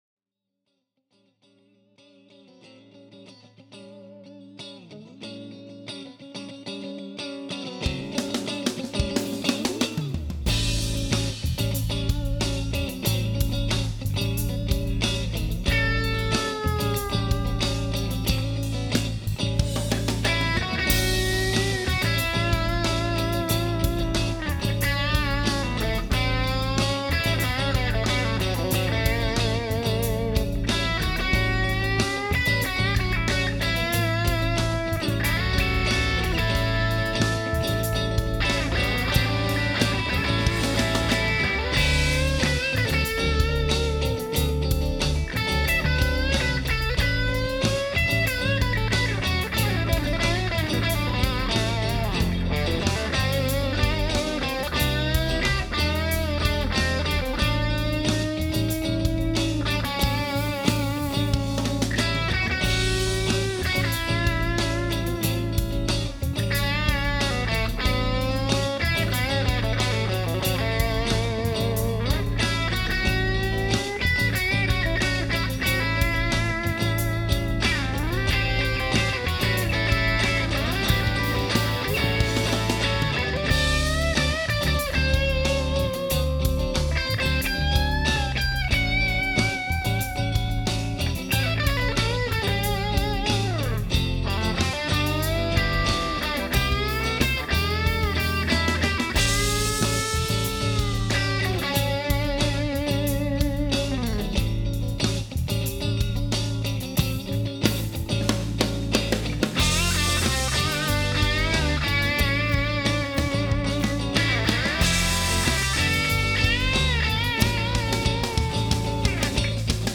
Now, here’s a clip of the song with the Fane Medusa 150.
The mids and highs are still present and incredibly articulate, but they’re so much less piercing! And one thing that I noticed immediately with the Medusa is the clarity of the notes through the entire EQ spectrum, whereas the Red Fang seemed to lose a bit of clarity at high-gain settings – especially when I play those transition chords.
I also removed the wah from the second clip because I didn’t feel the need to mix it up.
By the way, both clips were recorded at conversation levels using the fantastic Aracom PRX150-Pro attenuator, by far the best attenuator on the planet, from my perspective.